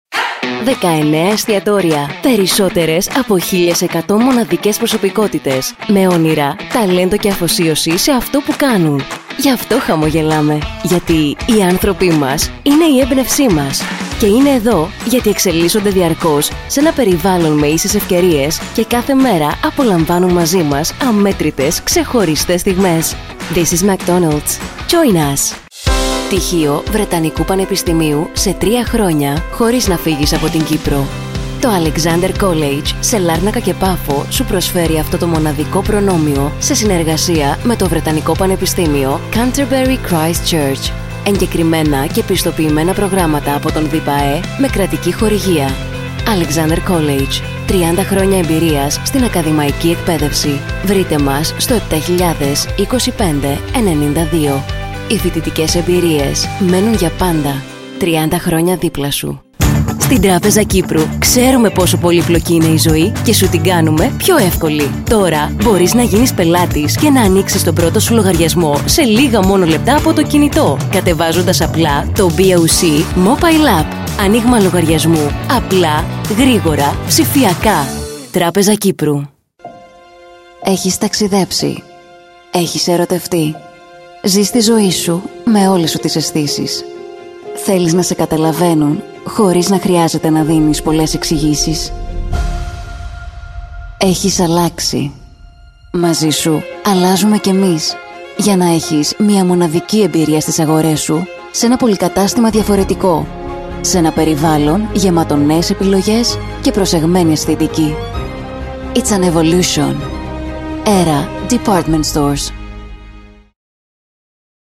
Greek voice over
Corporate